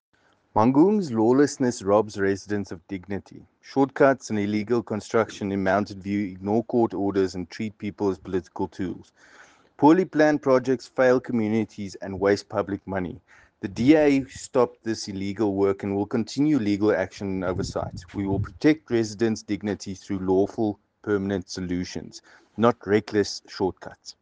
English soundbite by Cllr Paul Kotzé,